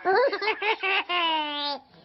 peekaboo2.ogg